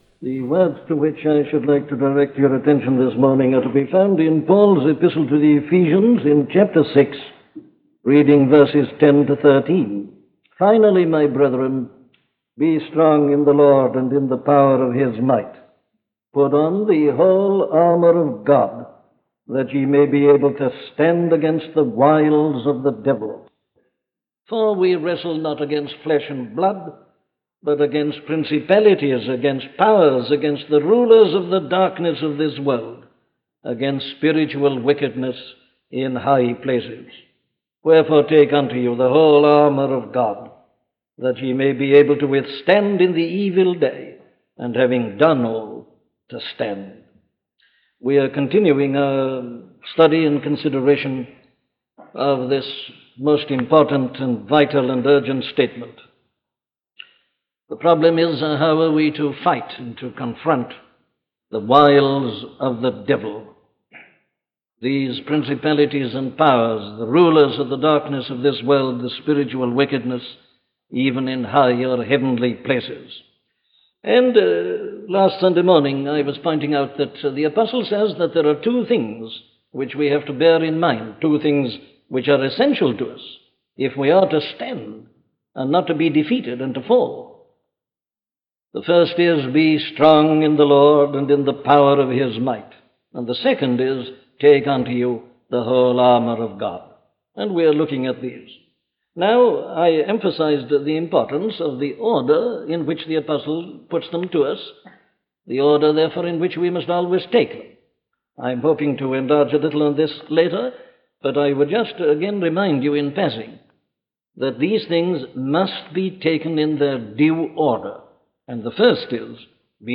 Listen to the sermon on Ephesians 6:10-13 'God's Battle; not Ours' by Dr. Martyn Lloyd-Jones